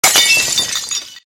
Catégorie SMS